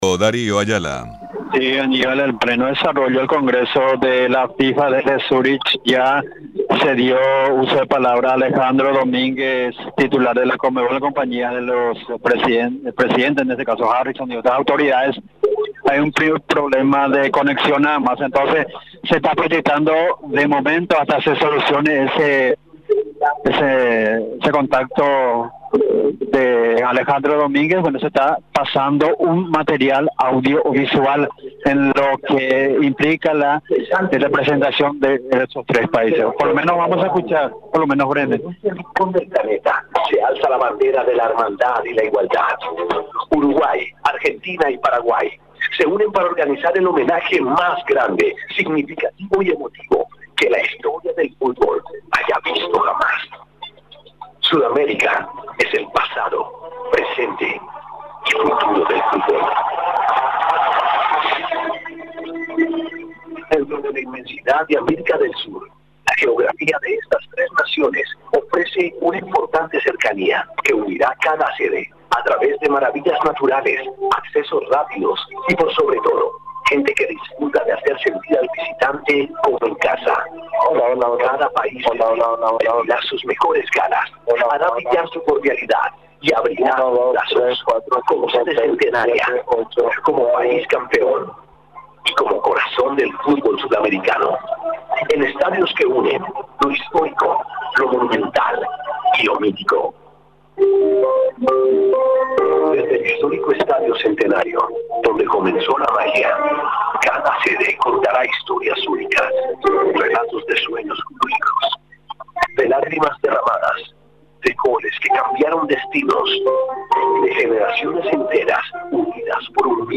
El anuncio se realizó durante el congreso de la Federación Internacional de Fútbol Asociado, realizado este miércoles en la sede de la Confederación Sudamericana de Fútbol, situado en la ciudad de Luque, Paraguay.